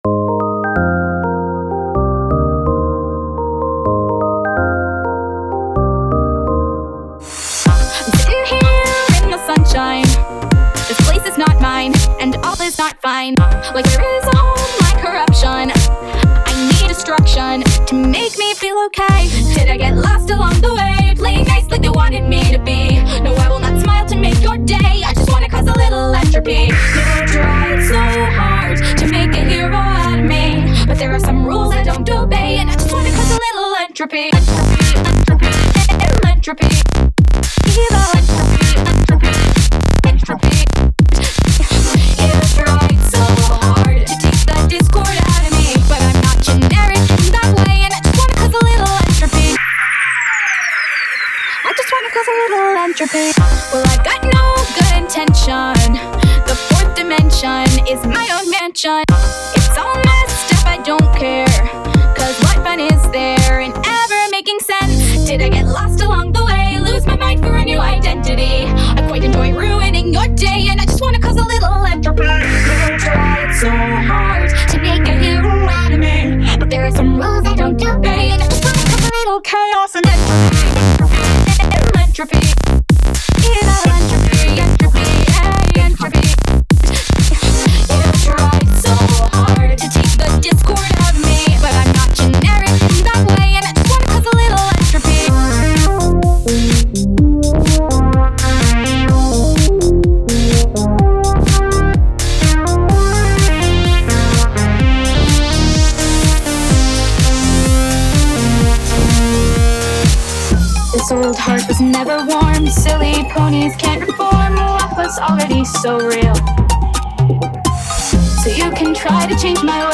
8759 music